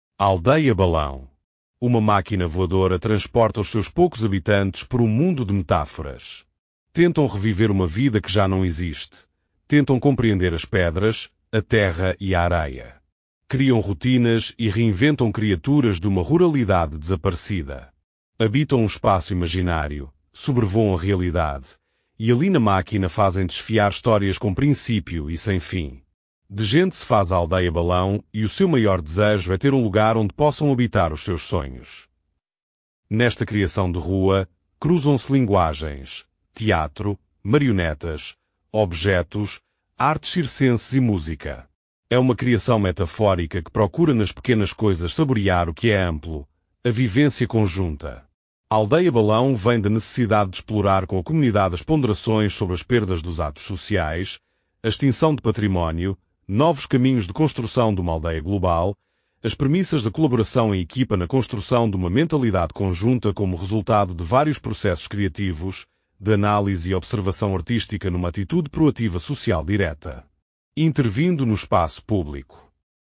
este áudio guia possui 13 faixas e duração de 00:19:43, num total de 13.9 Mb